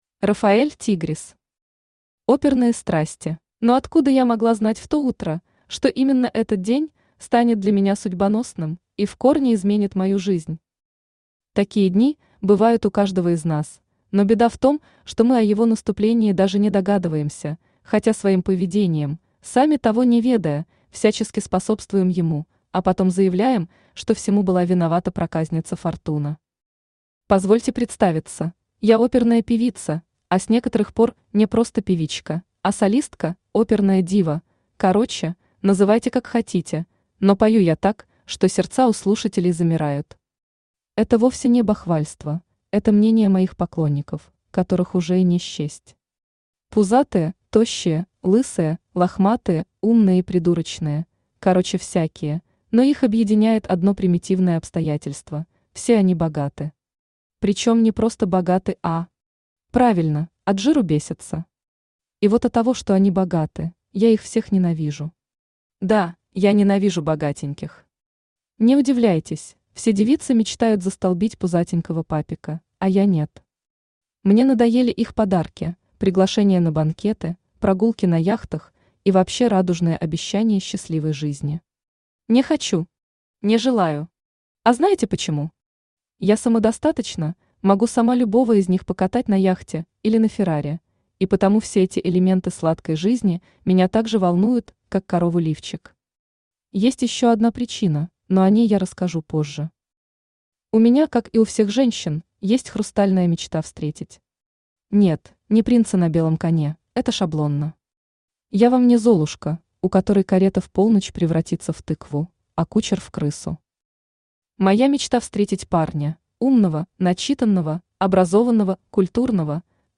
Аудиокнига Оперные страсти | Библиотека аудиокниг
Aудиокнига Оперные страсти Автор Рафаэль Тигрис Читает аудиокнигу Авточтец ЛитРес.